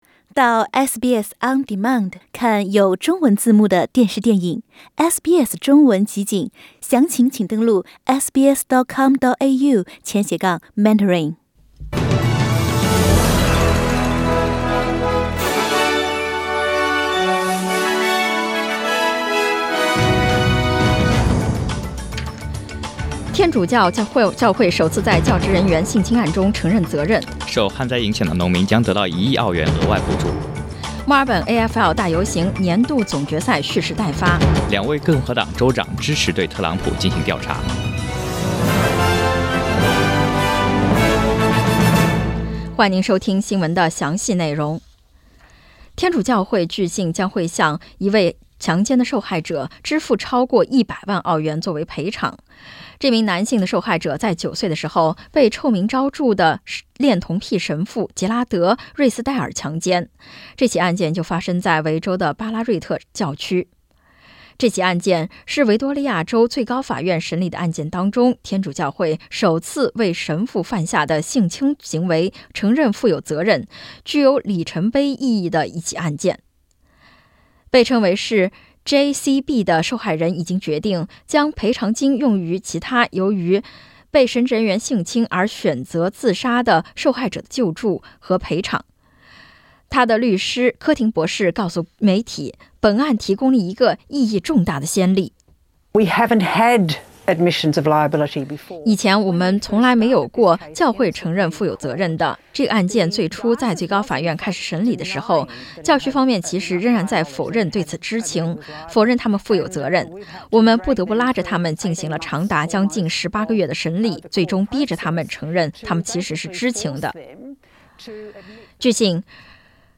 SBS 早新闻 （9月28日）